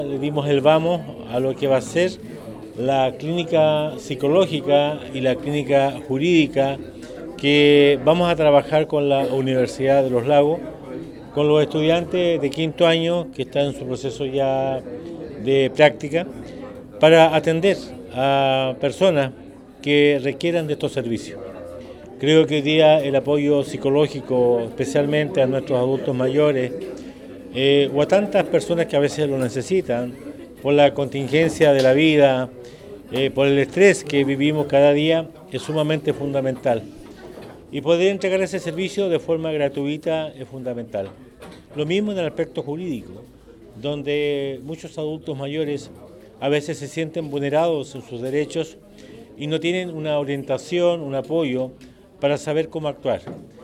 El Alcalde Carrillo, indicó que gracias a esta alianza entre el municipio y la Universidad de Los Lagos permitirá prestar diversos servicios a la comunidad, en el ámbito legal y en atenciones en el área de la salud mental.